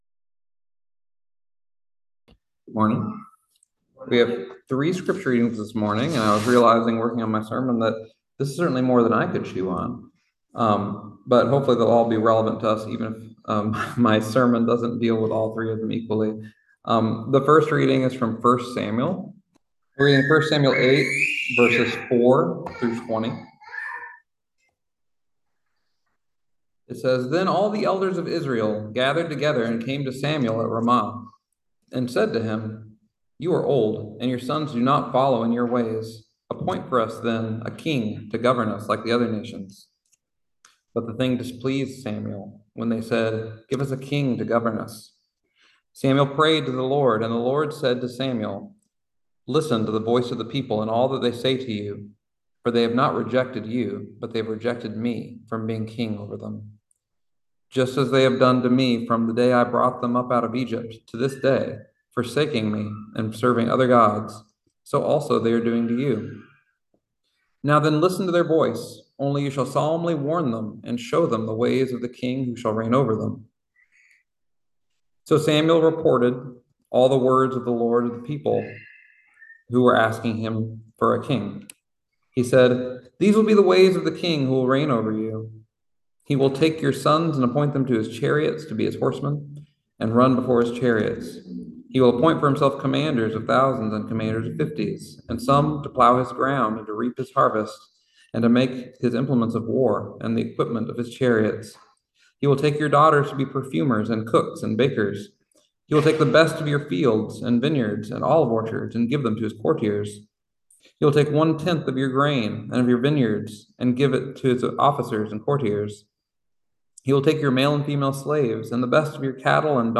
Message for June 9, 2024